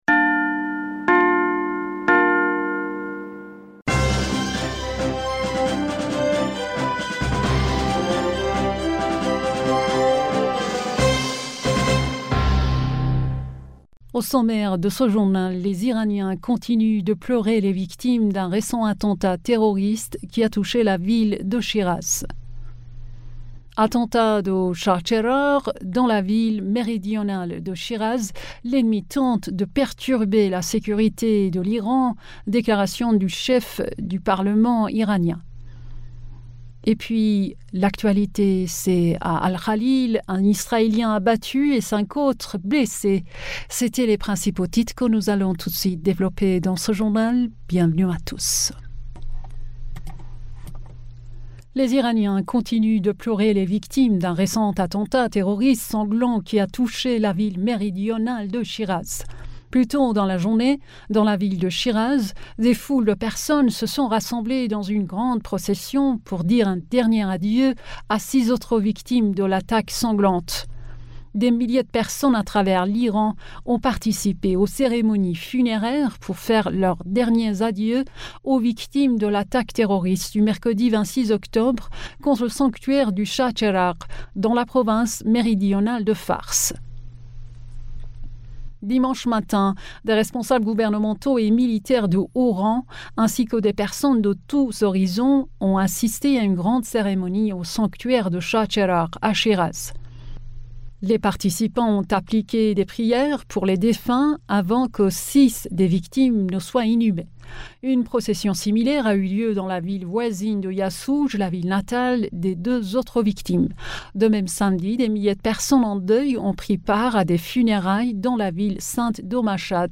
Bulletin d'information Du 30 Octobre